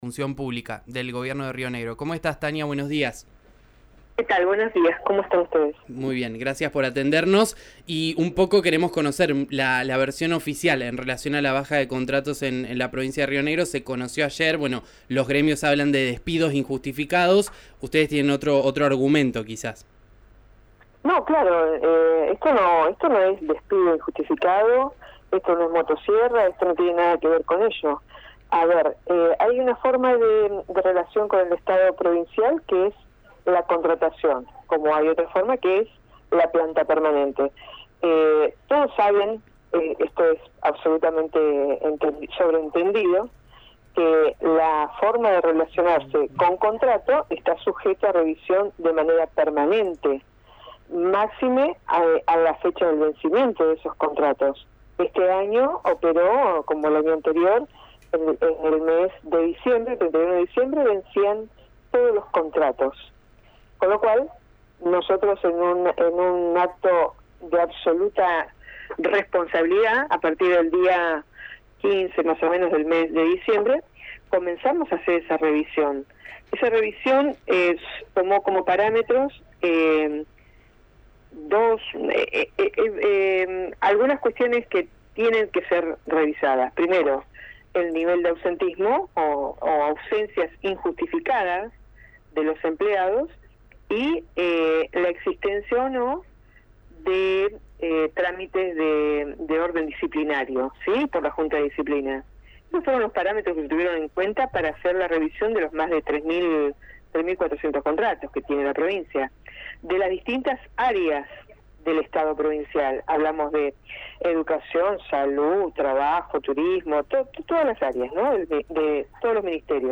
Escuchá a Tania Lastra en RÍO NEGRO RADIO: